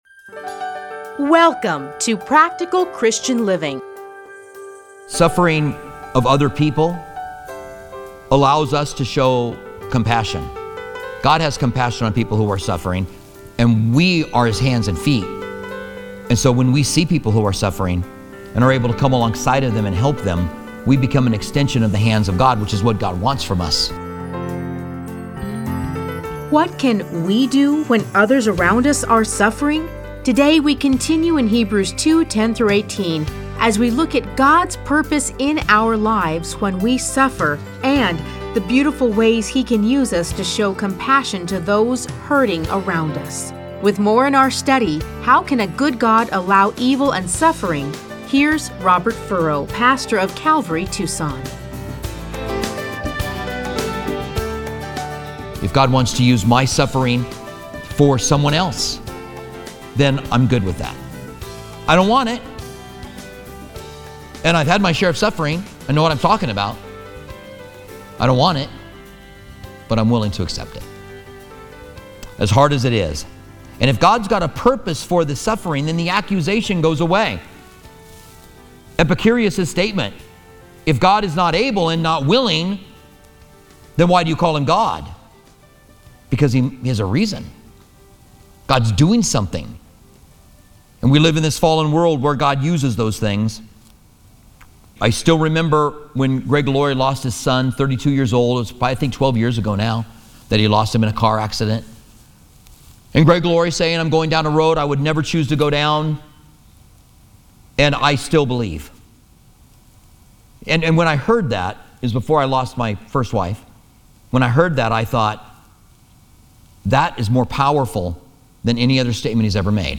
Listen to a teaching from Hebrews 2:10-18.